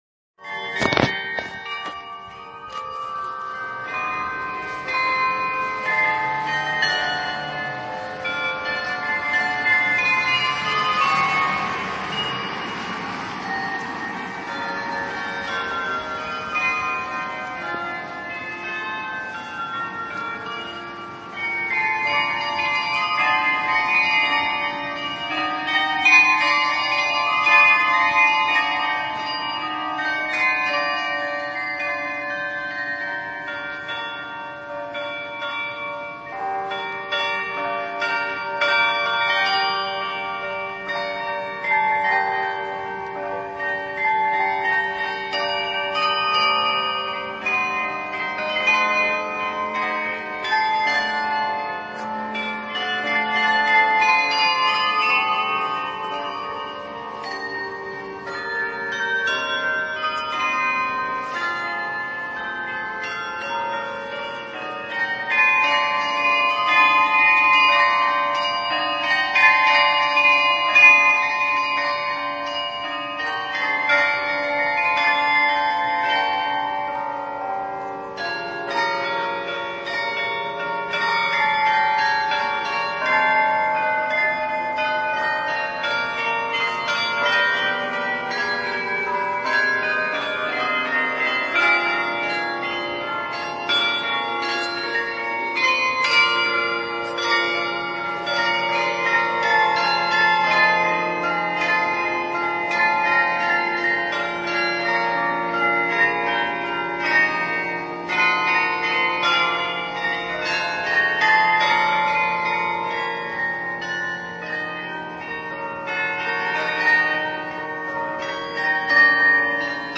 Auf dem Rathausplatz hörte ich da Carillon bekannte Melodien spielen.
Carillon-.mp3